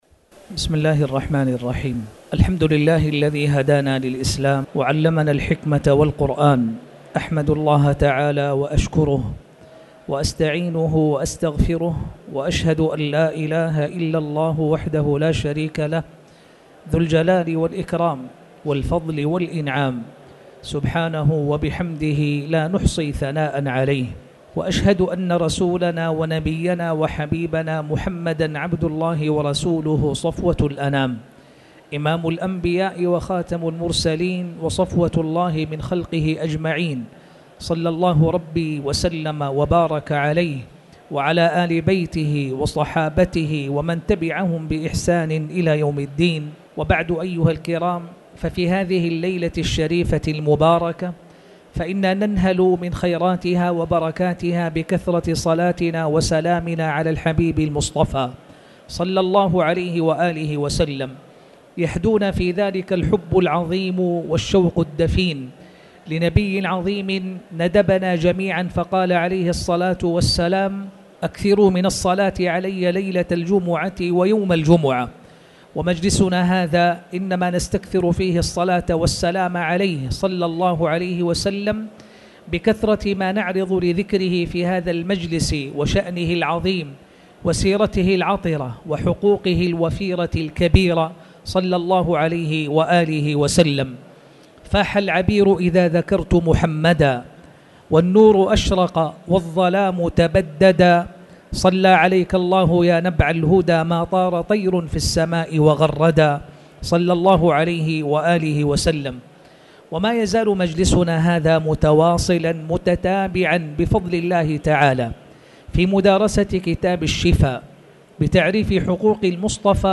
تاريخ النشر ٨ جمادى الأولى ١٤٣٩ هـ المكان: المسجد الحرام الشيخ